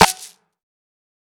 TC3Snare17.wav